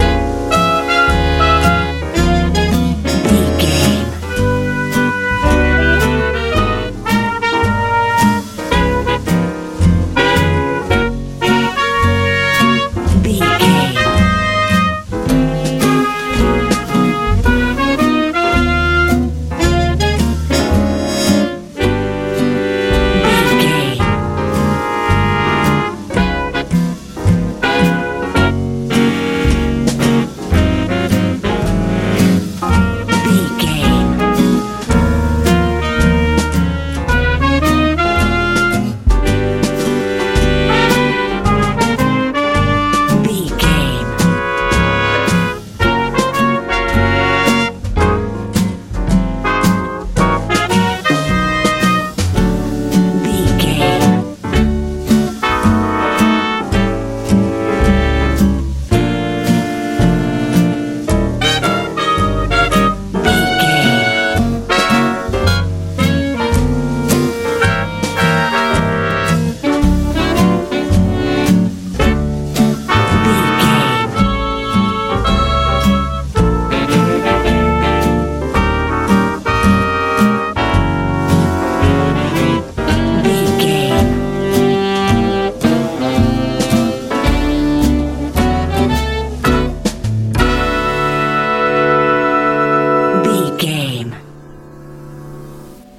Ionian/Major
groovy
elegant
mellow
piano
horns
drums
bass guitar